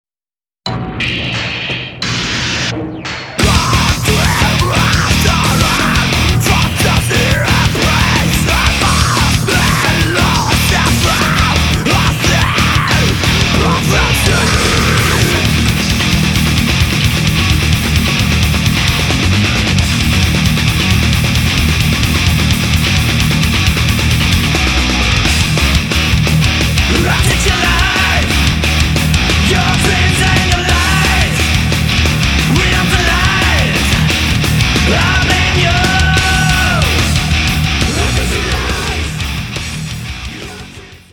*Genre: Melodic Thrash/Death Metal